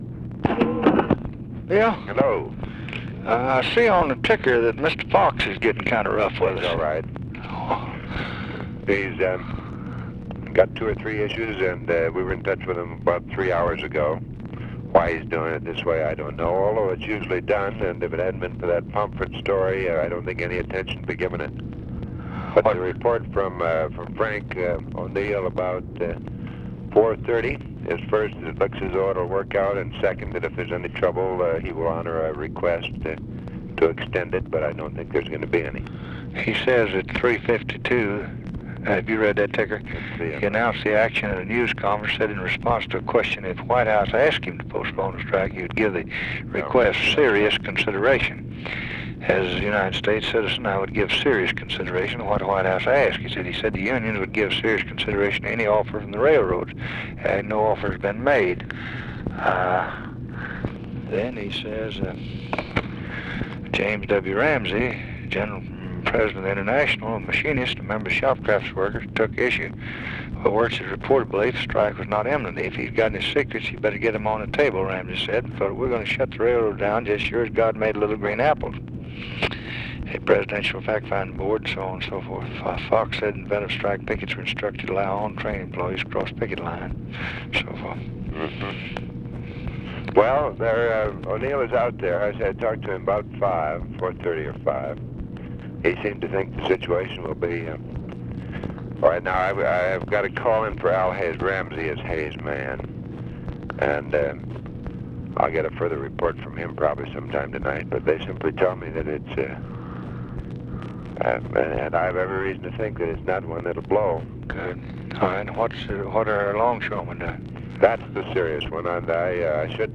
Conversation with WILLARD WIRTZ, November 17, 1964
Secret White House Tapes